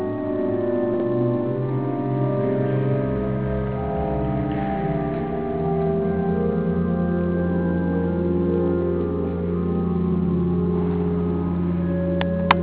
背後にはパイプオルガンが堂々と構えている。
そしてパイプオルガンの響き…(-_-)
演奏会ではなく練習をしているような感じの
弾きかたではあったが、聖堂全体をやわらかく震わせるような
カメラについていた録音機能を初めて使ってみたのだが、